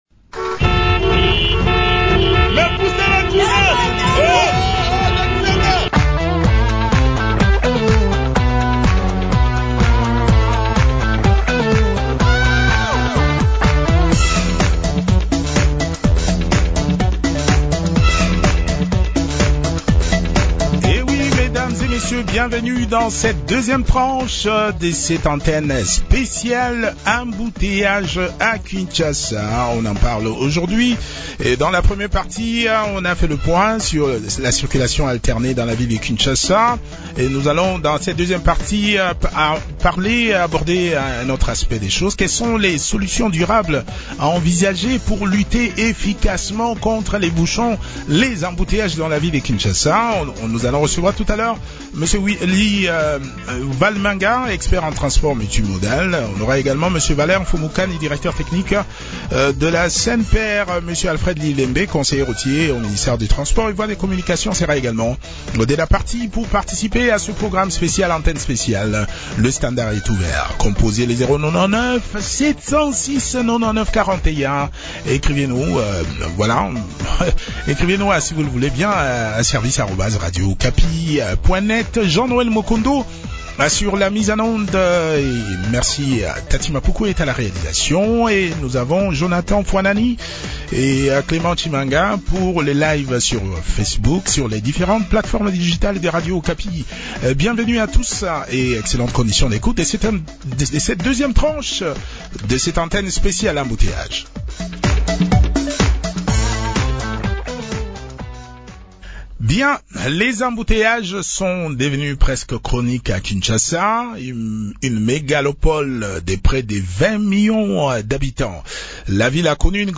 respectivement expert en transport multimodal et démographe ont également pris part à cet entretien.